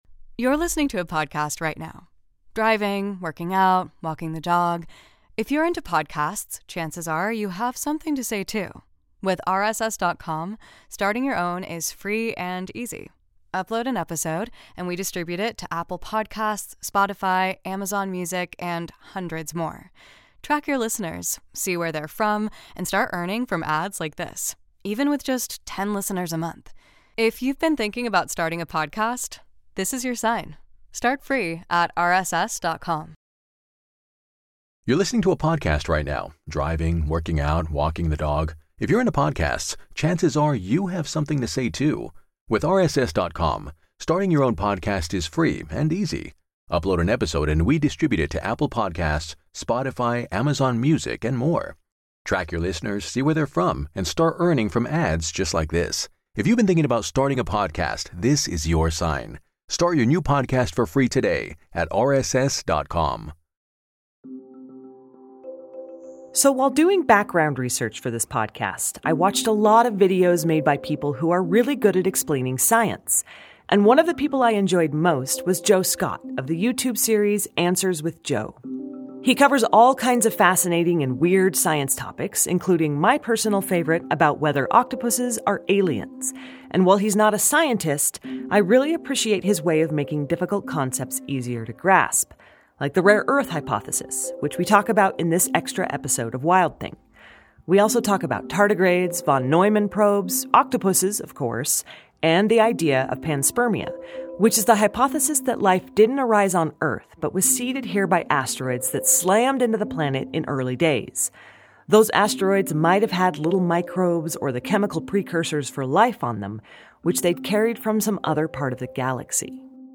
S2 Bonus Interview